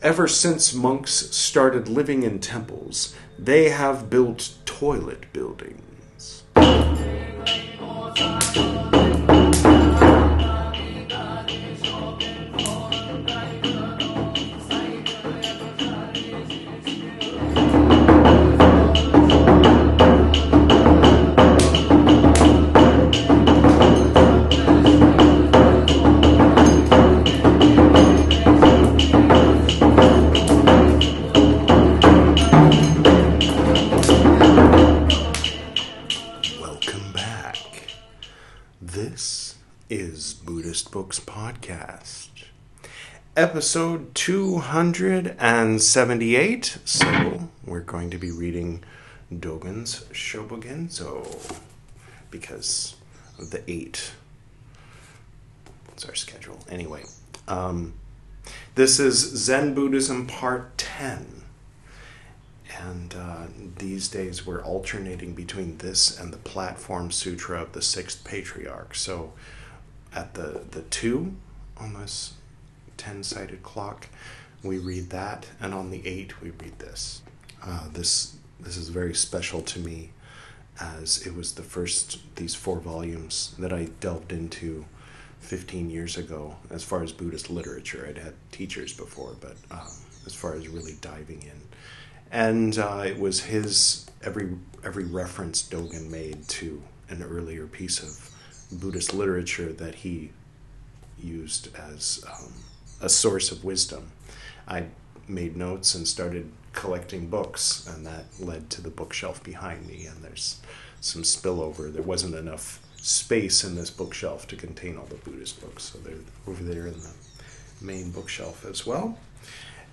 In this episode, we’ll be reading Eihei Dōgen’s 'Shobogenzo' the chapter entitled 'Senjo' meaning 'Washing' which he wrote in 1239.